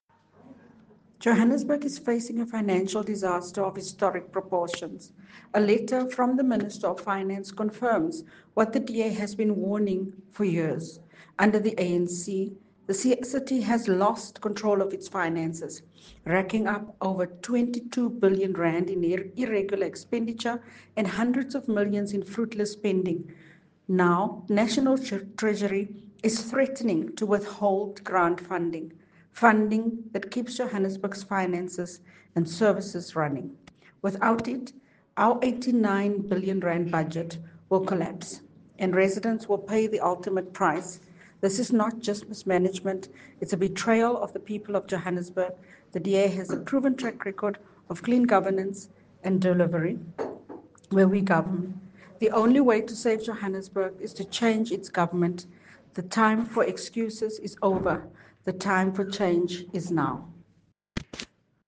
Issued by Cllr Belinda Kayser-Echeozonjoku – DA Johannesburg Caucus Leader
Note to Editors: Please find the letter here, and English and Afrikaans soundbites